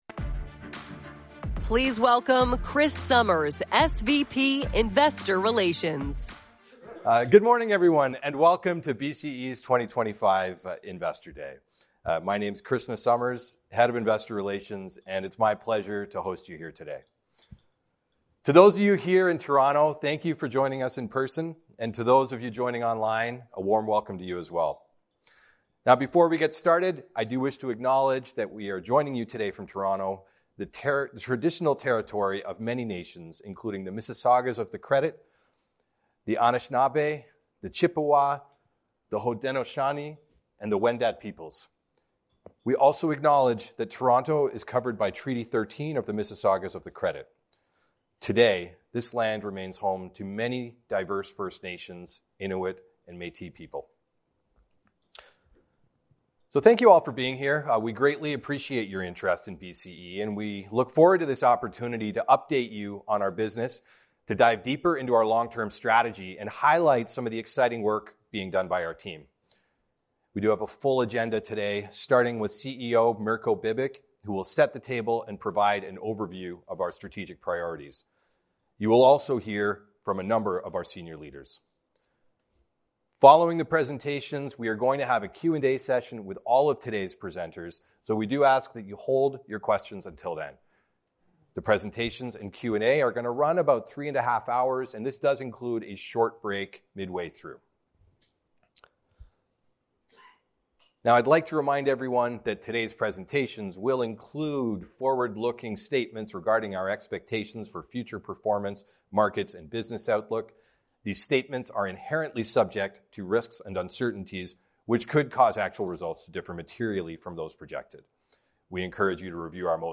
BCE held an Investor Day in Toronto on Tuesday, October 14, 2025, from 8:30 am to 12:00 pm ET.
The event featured presentations from BCE's Senior Executive leadership team outlining the company's strategic priorities, capital markets strategy and financial outlook. The event also included a Q&A session for institutional investors and analysts to engage with company executives.